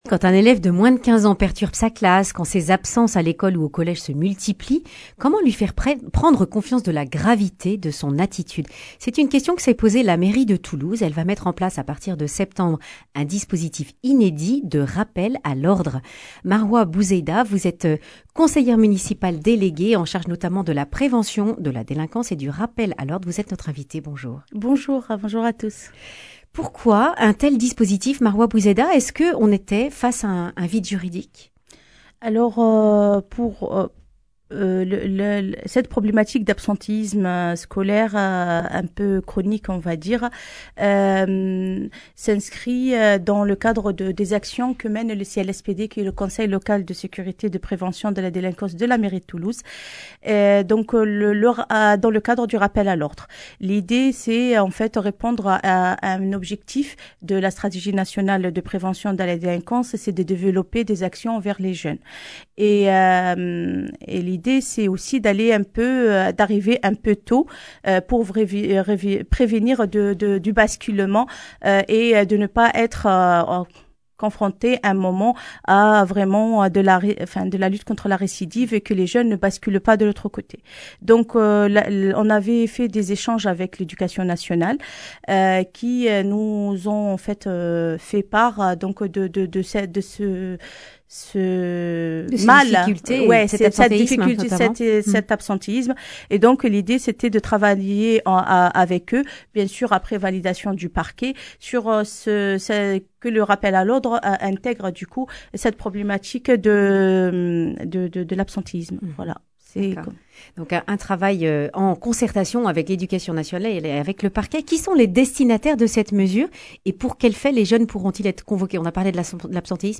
Maroua Bouzaida, conseillère à la mairie de Toulouse, en charge de la prévention de la délinquance.